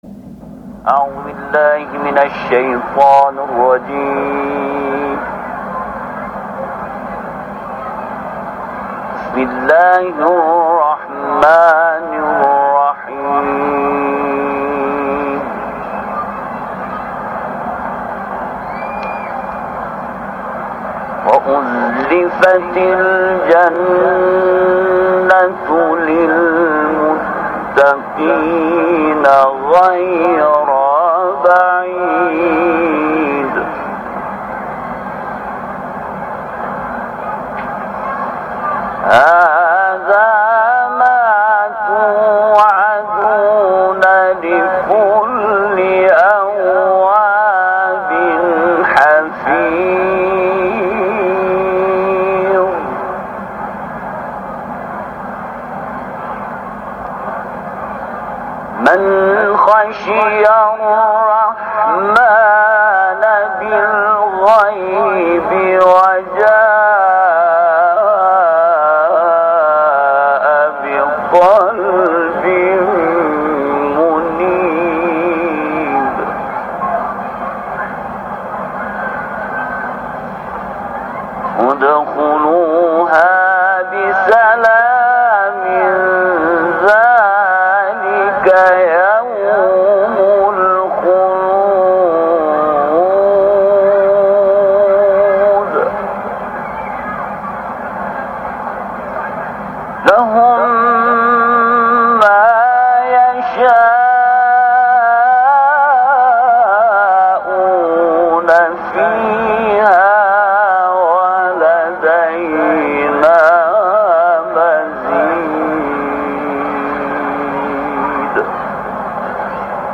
تلاوت
حرم مطهر رضوی